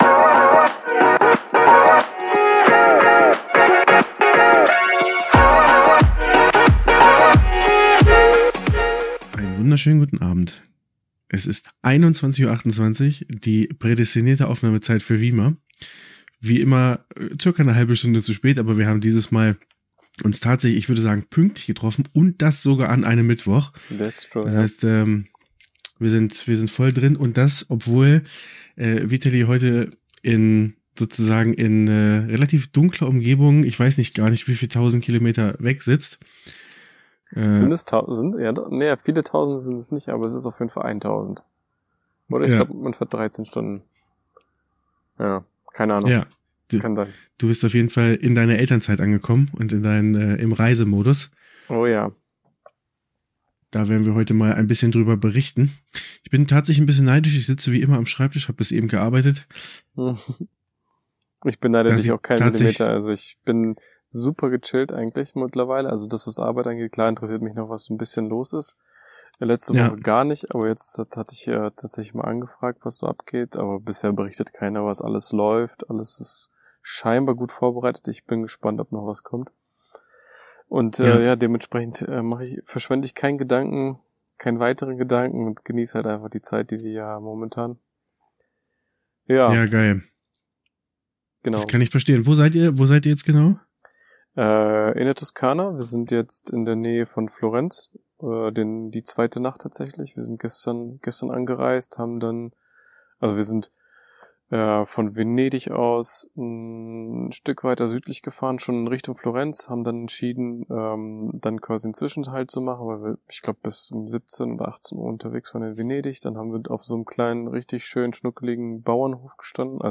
von einem Campingplatz in der Toskana. Freut euch auf eine spannende Folge mit vielen Hintergrundinfos zu so einer langen Reise mit zwei kleinen Kindern.